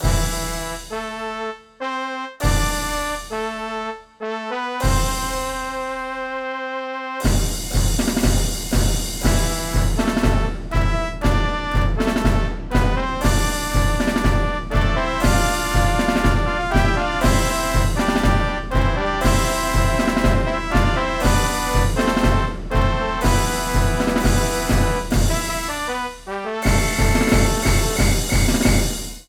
FIGHT SONG